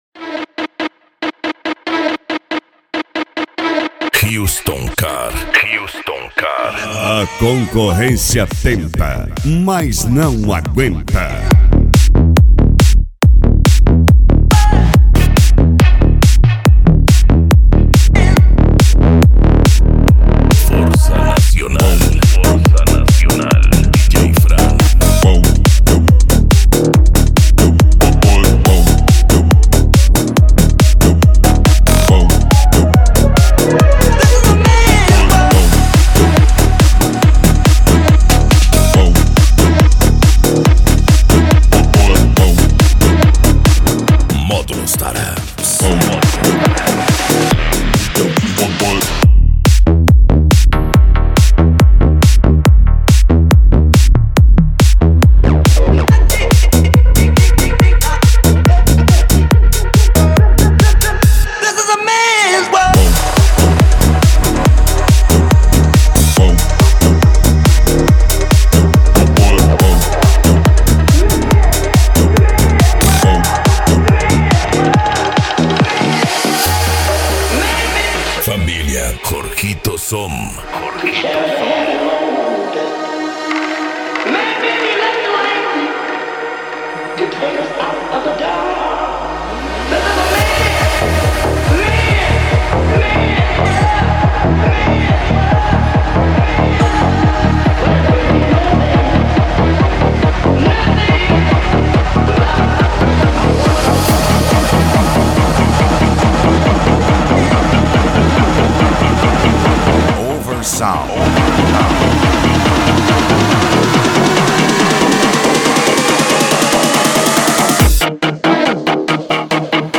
Bass
Cumbia
Funk
Musica Electronica
Remix